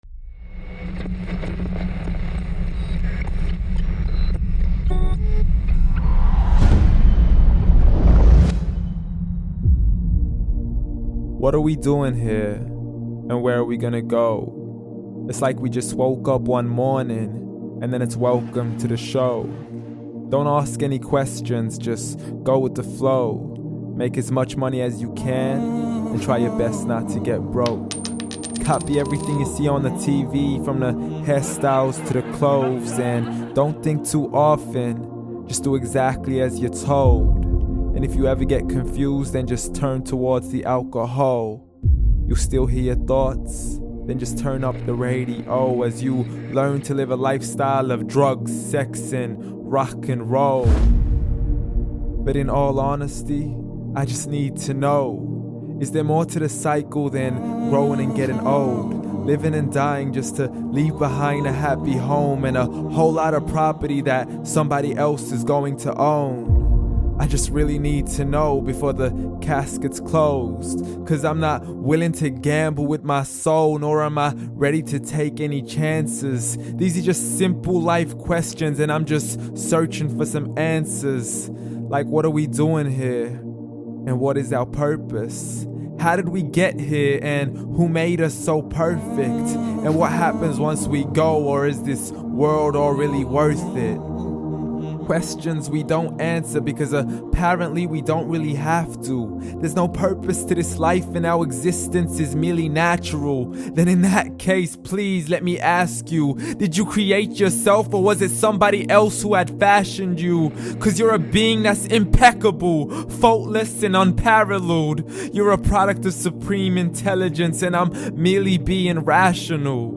The Meaning of Life – Spoken Word